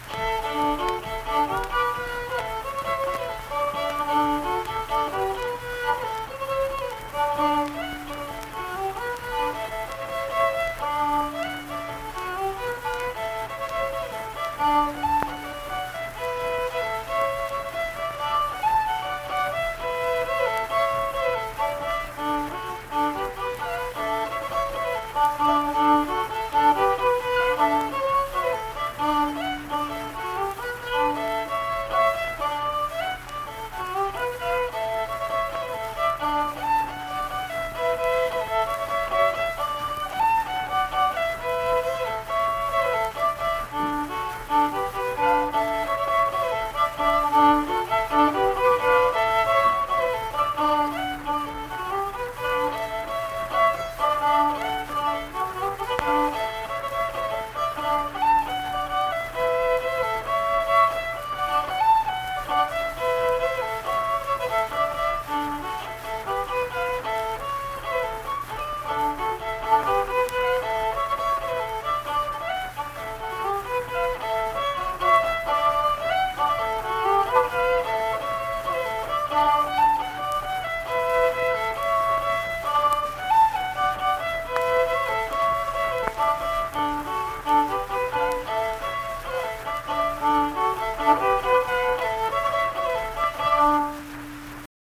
Unaccompanied fiddle music performance
Verse-refrain 3(3).
Instrumental Music
Fiddle